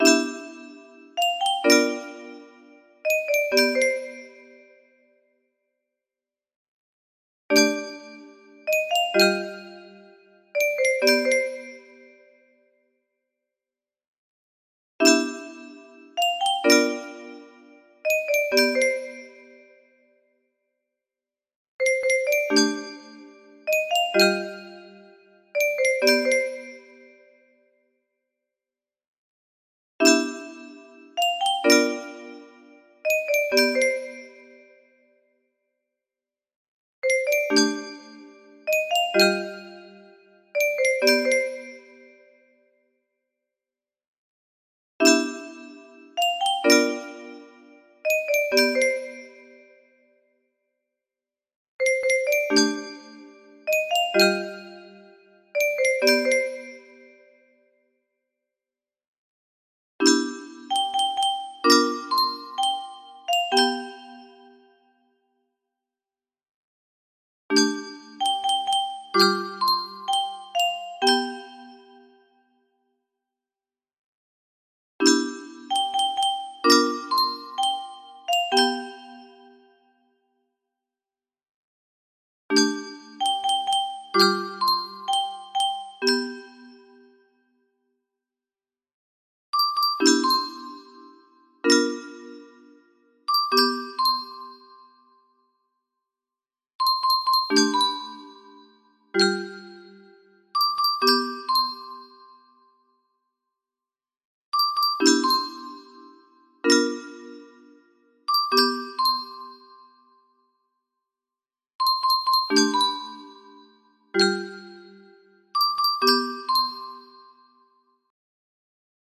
adapted for 30 notes :)